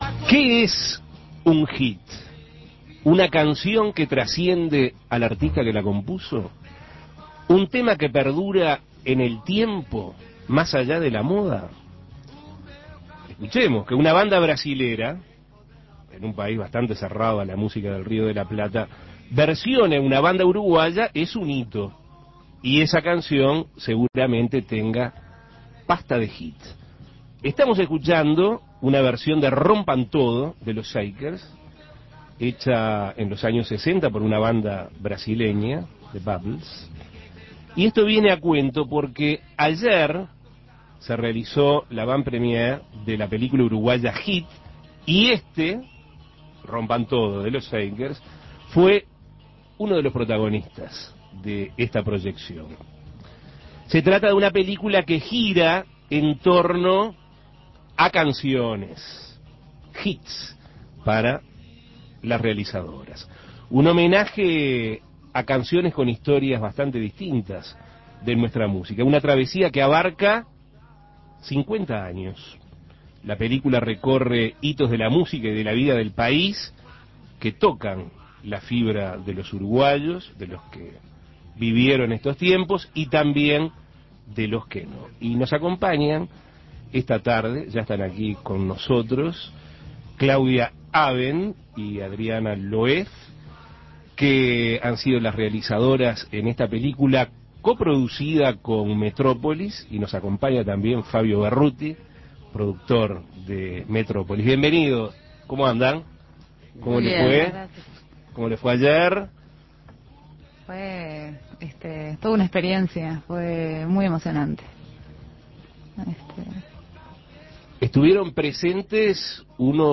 Entrevistas Música para ver Imprimir A- A A+ El jueves 6 de marzo se realizó la avant premiere de la nueva película uruguaya Hit.